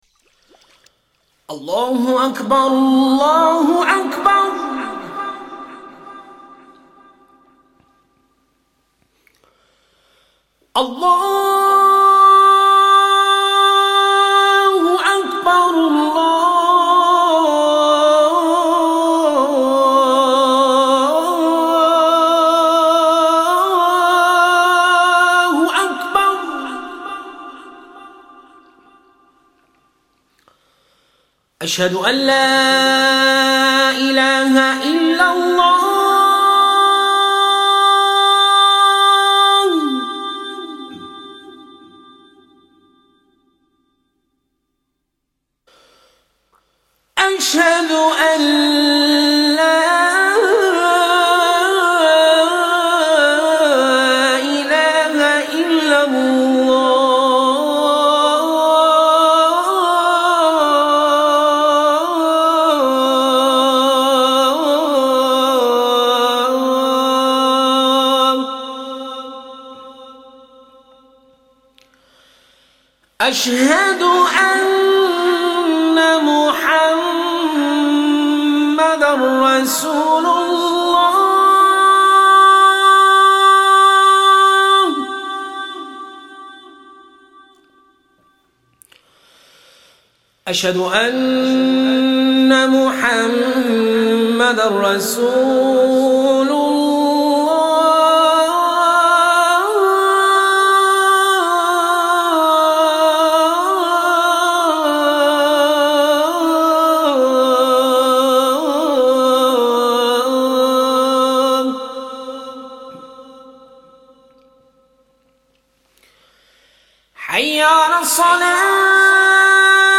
Голос муллы читающего Азан перед молитвой